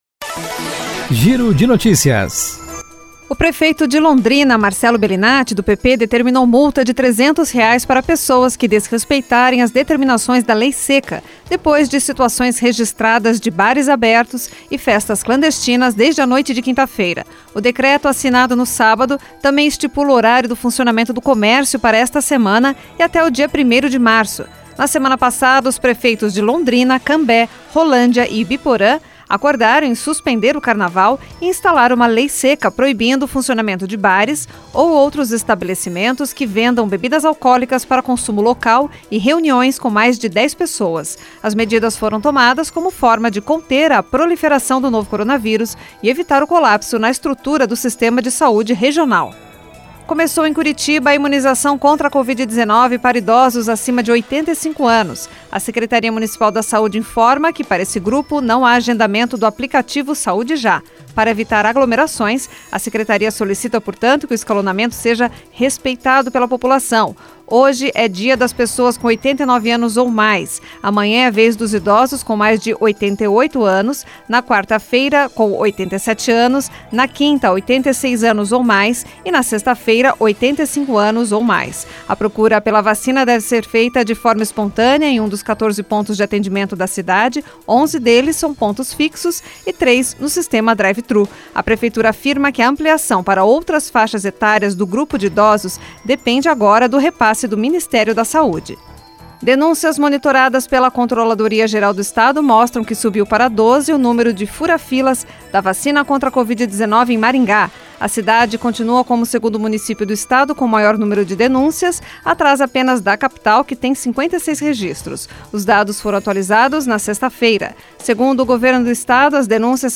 Por Jornalismo.
Giro de Notícias COM TRILHA